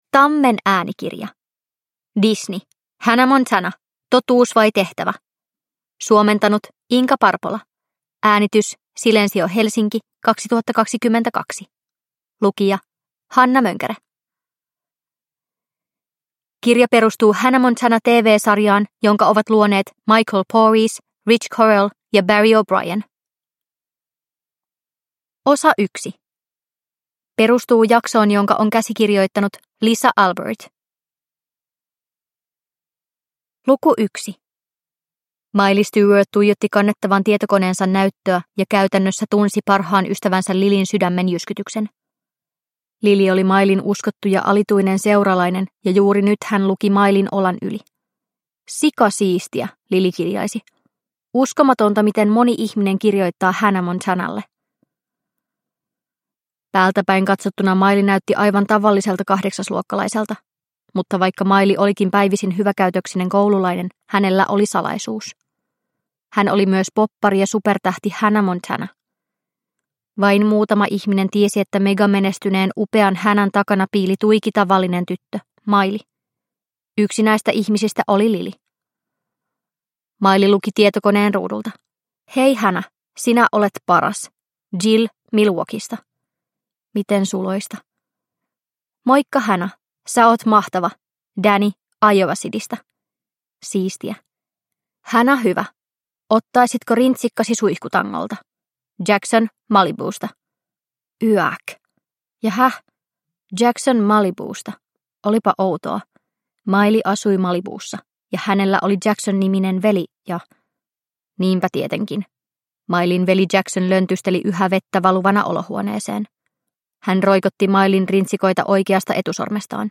Hannah Montana. Totuus vai tehtävä? – Ljudbok – Laddas ner